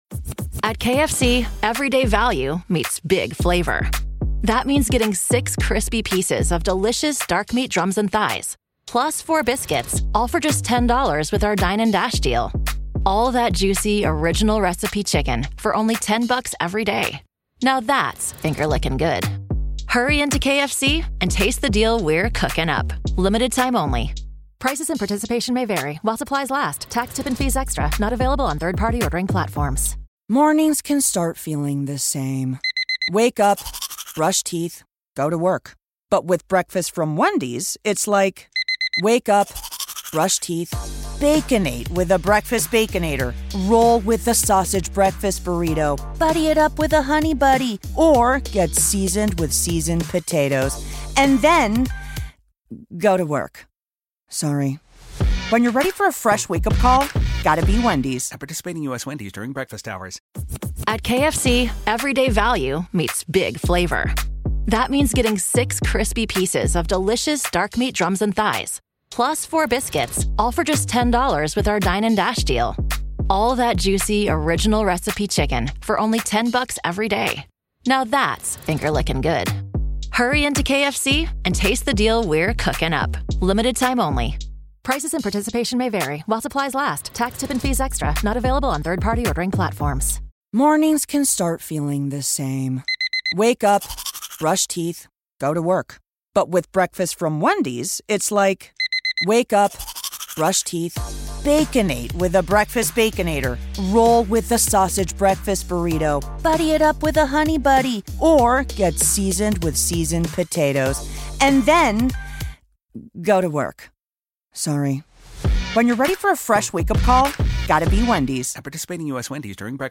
On Location: The Historic Wolf Hotel, Part Two
This is Part Two of our conversation.